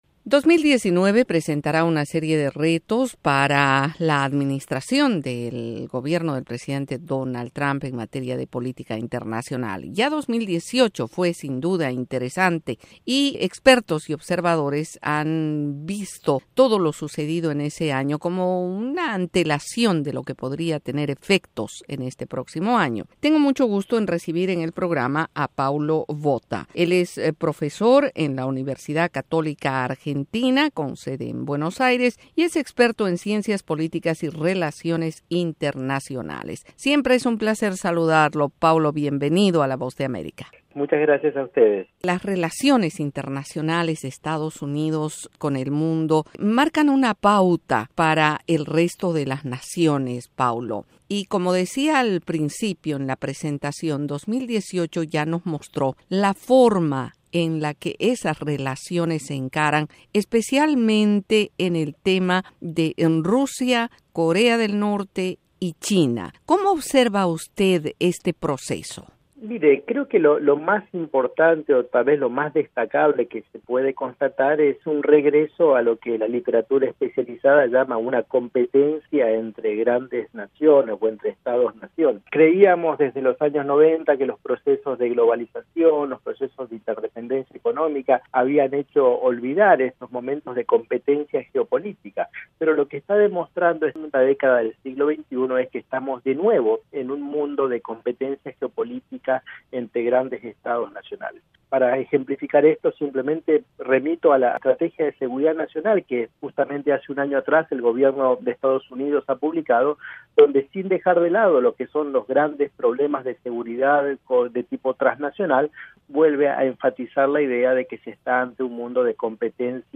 el tema se analiza en esta entrevista de la Voz de América